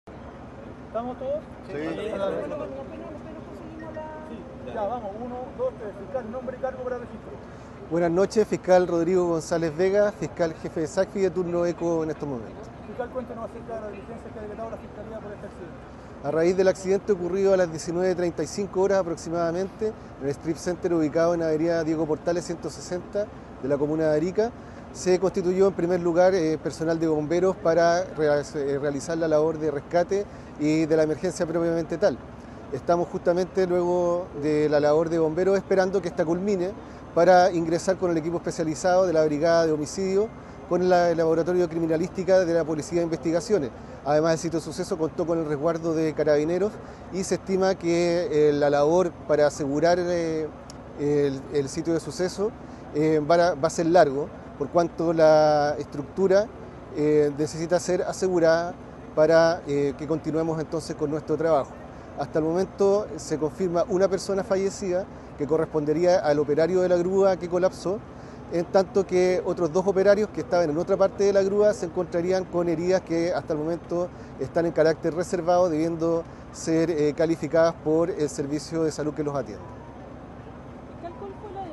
El fiscal Rodrigo González Vega informó que se ha dispuesto una investigación exhaustiva para fijar responsabilidades y analizar las condiciones de seguridad en el lugar.
rodrigo-gonxalez-fiscal-arica-.mp3